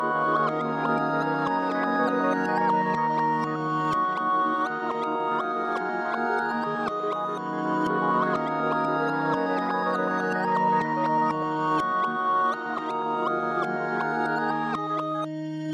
合成器陷阱循环E 122 Bpm
描述：从我做的一个陷阱循环中提取合成器部分。也可用于Hip Hop。
标签： 122 bpm Trap Loops Synth Loops 2.65 MB wav Key : E
声道立体声